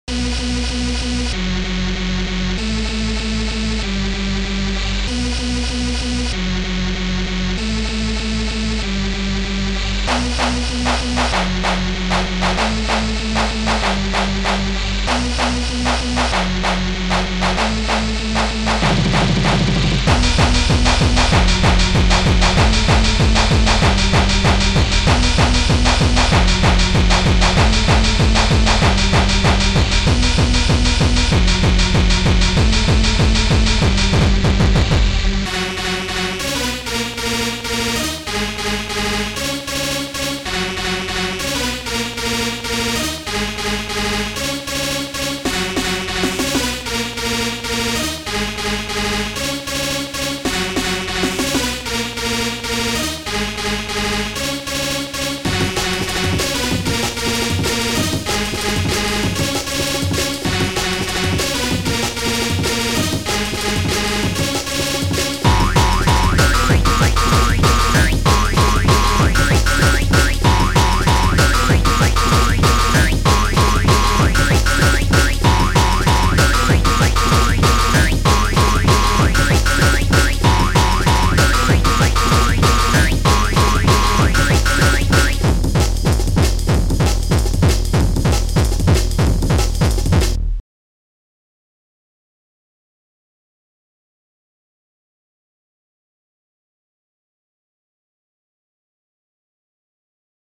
Protracker M.K.